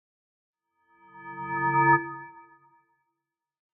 Minecraft Version Minecraft Version snapshot Latest Release | Latest Snapshot snapshot / assets / minecraft / sounds / block / bell / resonate.ogg Compare With Compare With Latest Release | Latest Snapshot
resonate.ogg